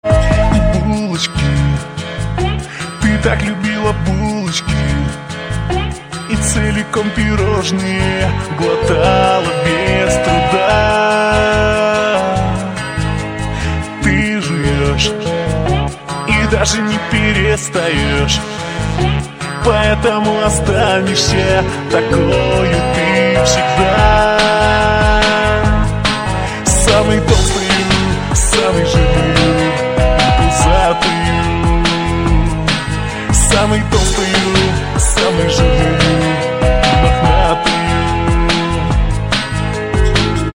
смешные